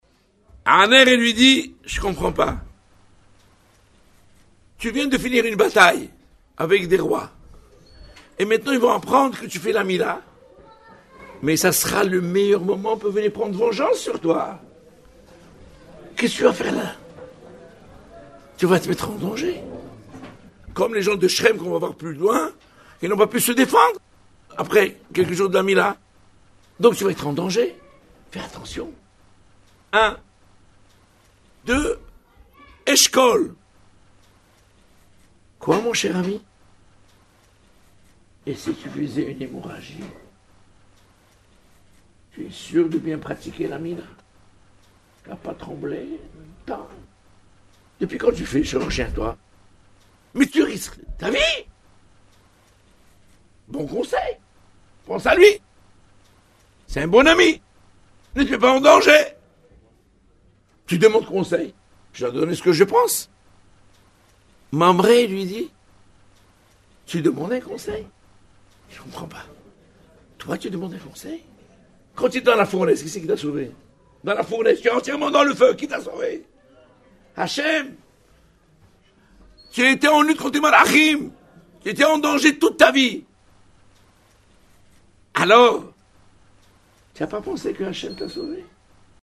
exposé